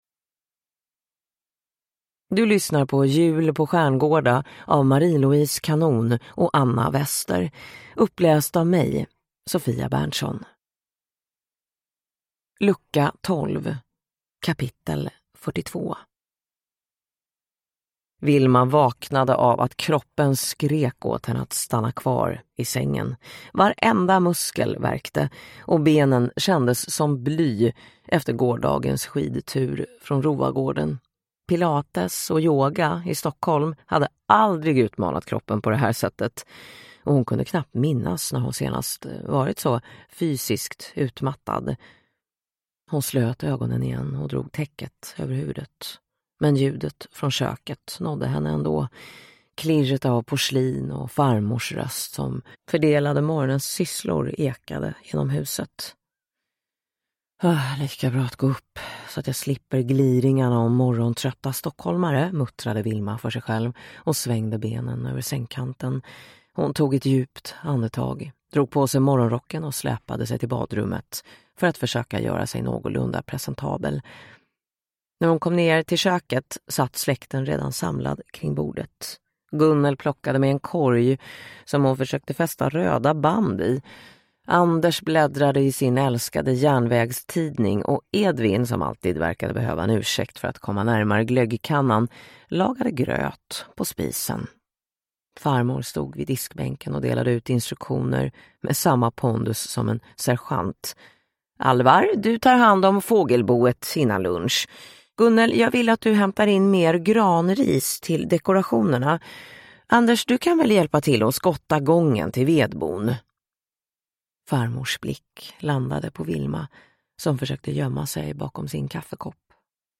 • Ljudbok